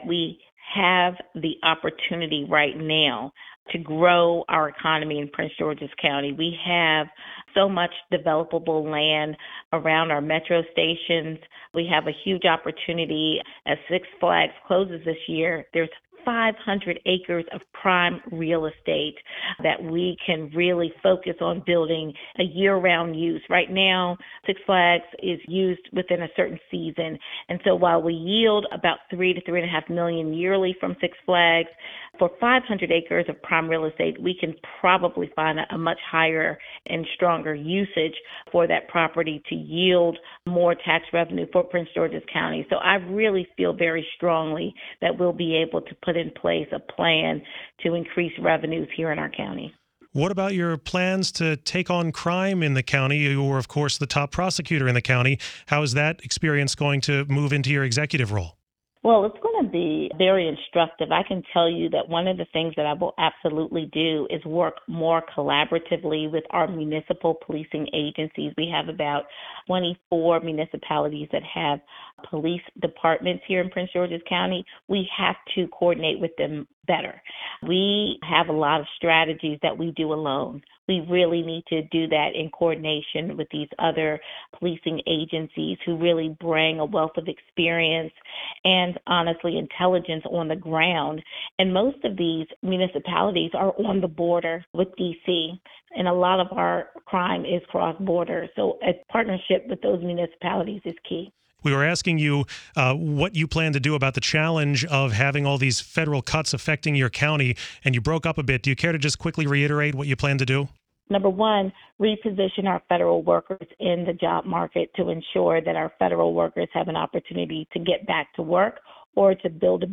Aisha Braveboy spoke to WTOP a day after unofficially winning a special election for county executive in Prince George's County.